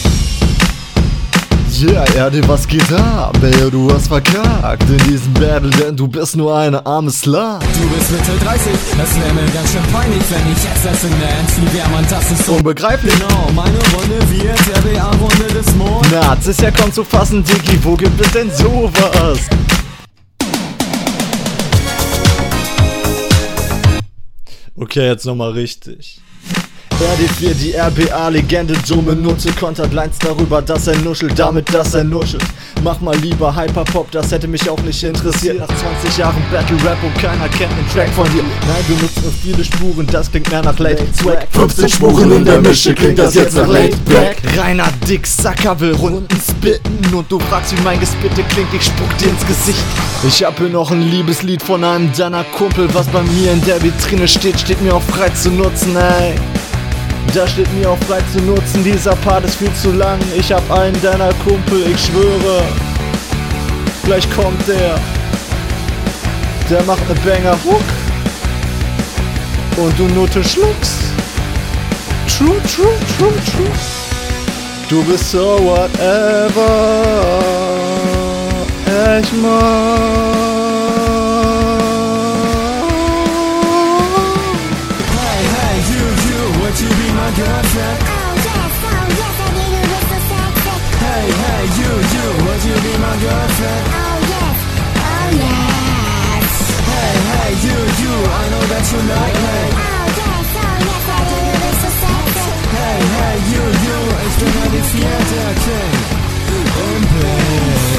die runde klingt nach freestyle, klar deine schwächste hier, mixing klingt hier auch sehr roh.
Viel zu abgehackt und komische Pause, Mische auch absolut absturz.